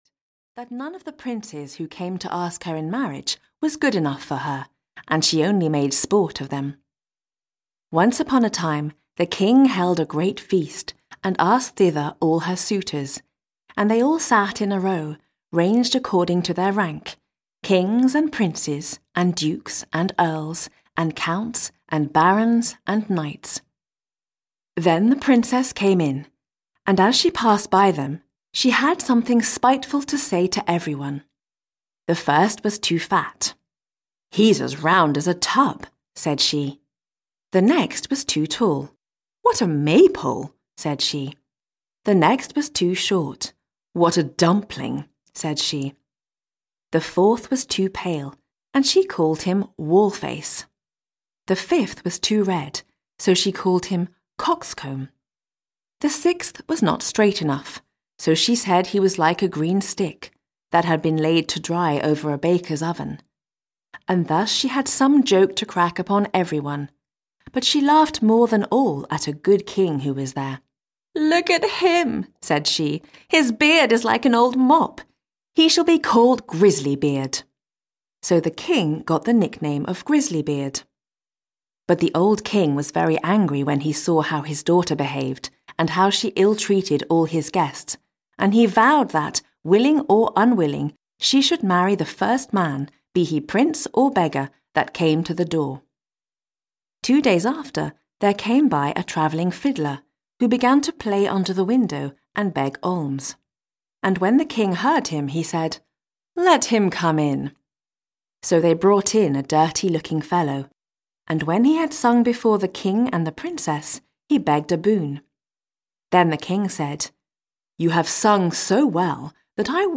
Audiobook 3 Stories by Brothers Grimm. 3 classic fairytales short stories by Brothers Grimm : Iron Hans, Cat-Skin and King Grisly Beard.
Ukázka z knihy